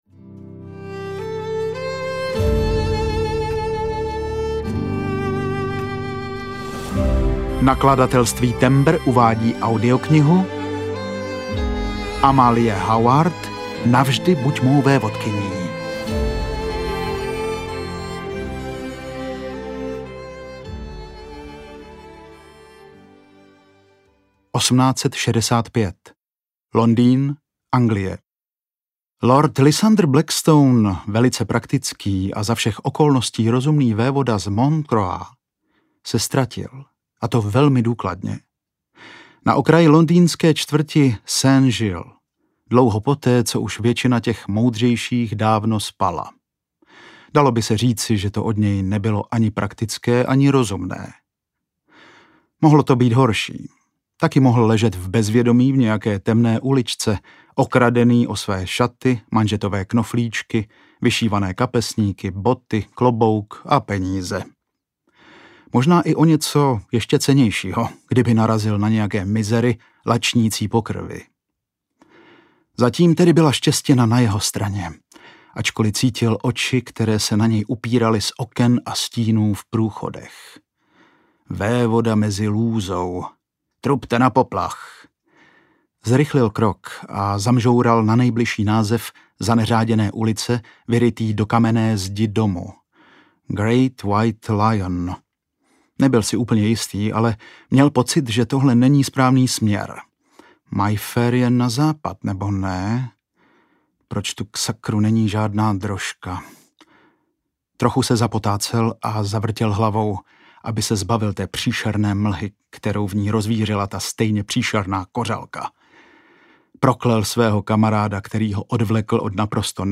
Navždy buď mou vévodkyní audiokniha
Ukázka z knihy
Hudba Pavel Holý | Natočeno ve studiu BEEP